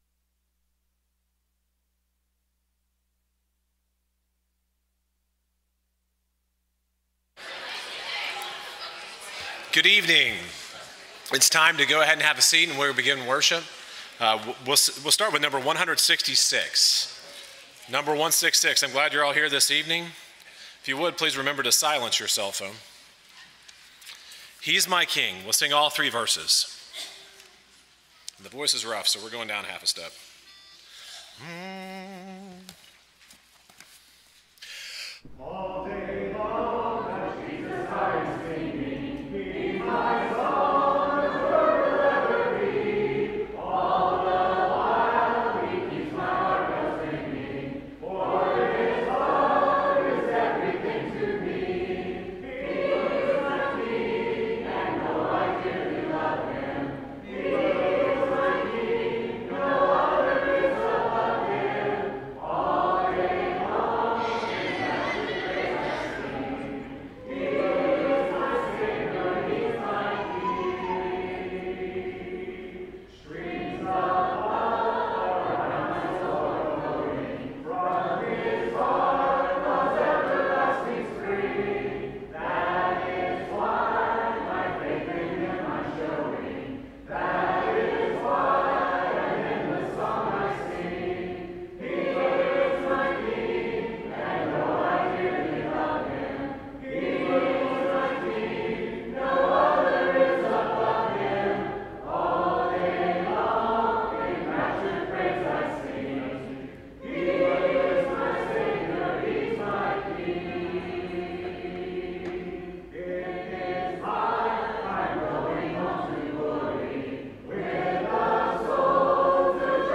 Ephesians 6:11, English Standard Version Series: Sunday PM Service